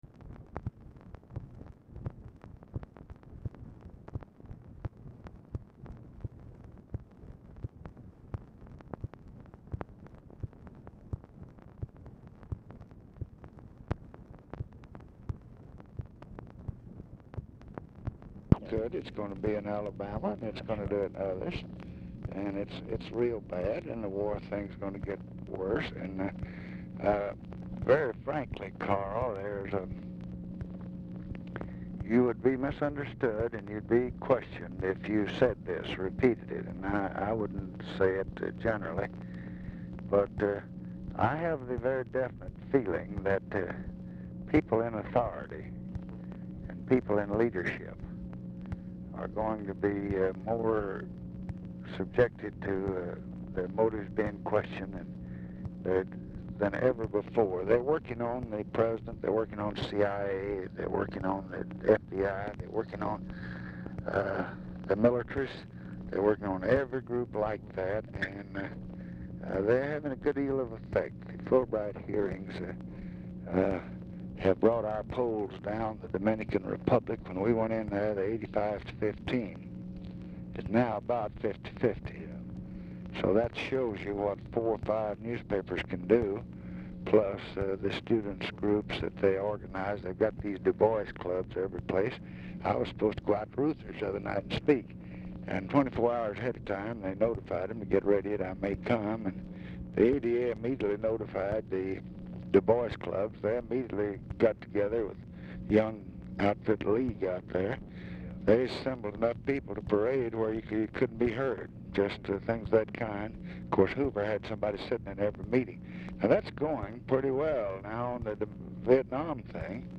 Telephone conversation # 10135, sound recording, LBJ and CARL SANDERS, 5/23/1966, 10:10AM | Discover LBJ
Format Dictation belt
Oval Office or unknown location